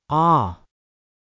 アメリカ英語の母音/ɑ/は、日本語の「ア」に近い音です(「ア」と「オ」の間の音と表現されることもあります)。
母音/ɑ/のみの発音
母音aのみの発音.mp3